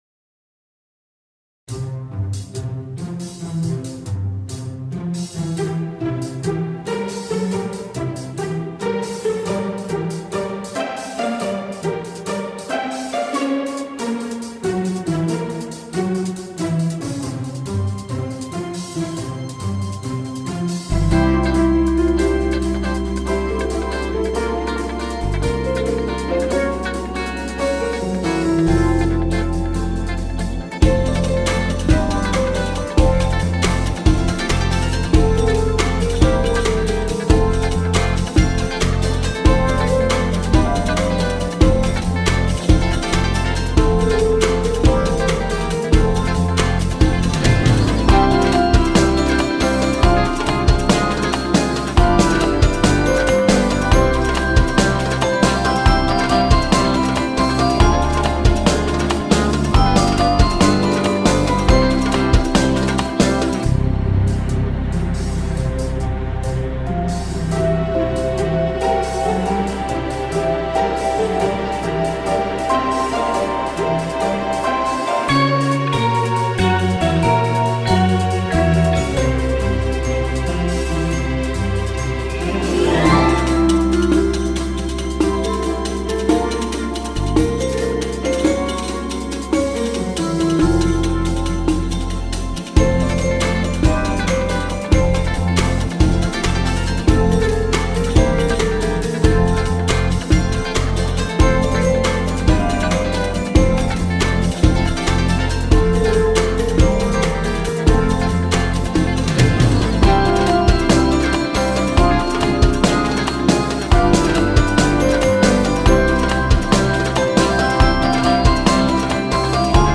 tema dizi müziği, neşeli eğlenceli enerjik fon müziği.